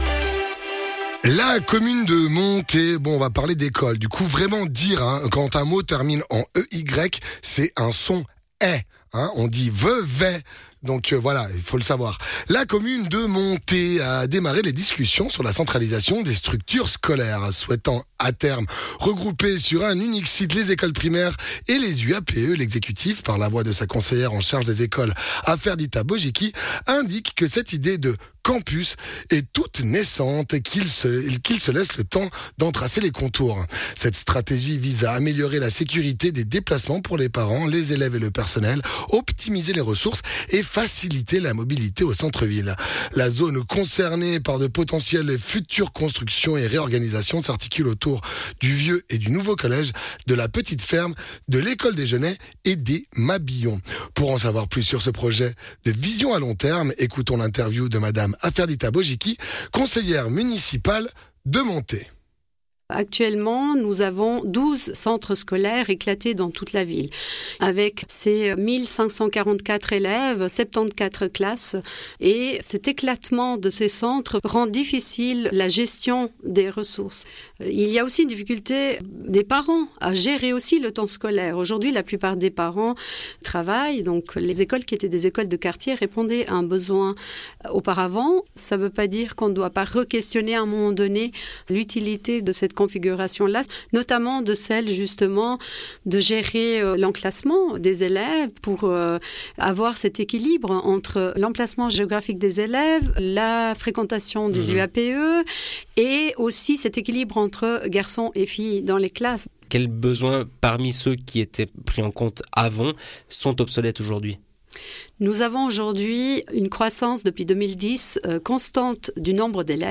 Intervenant(e) : Aferdita Bogiqi - conseillère municipale de Monthey.